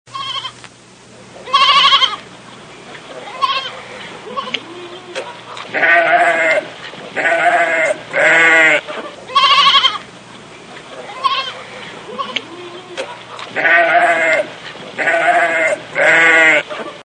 Sheep Ringtone
• Animal Ringtones